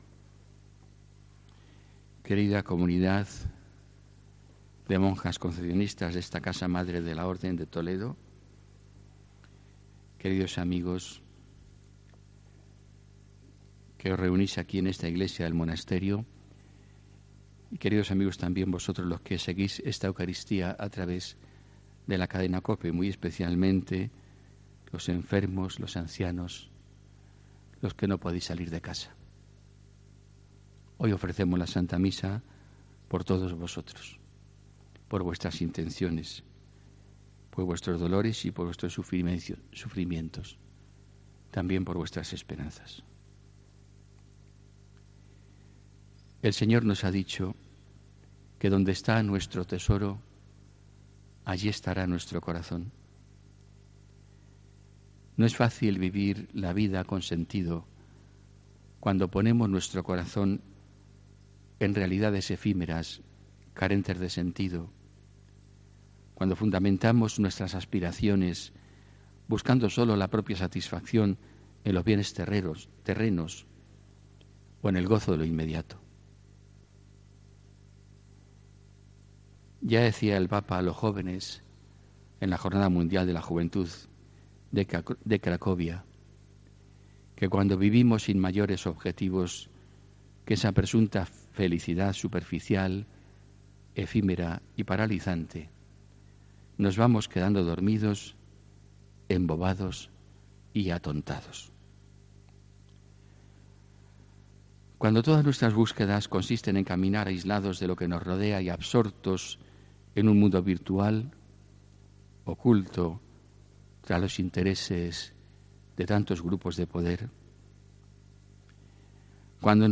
HOMILÍA 11 AGOSTO 2019